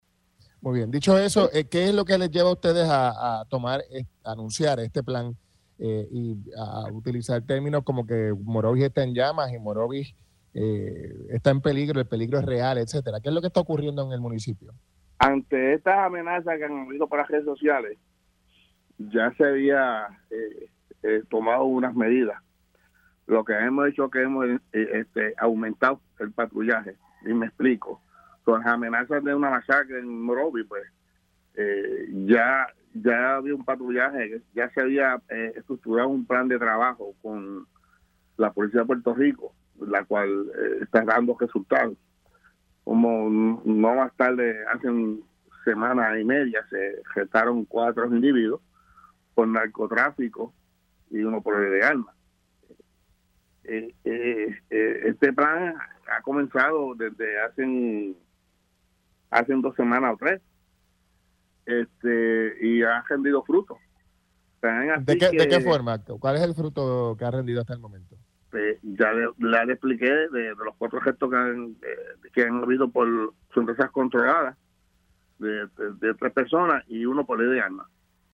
200-ALVIN-RODRIGUEZ-COMISIONADO-POLICIA-MOROVIS-RINDE-FRUTOS-PLAN-PREVENTIVO-Y-LOGRAN-ARRESTO-DE-VARIAS-PERSONAS.mp3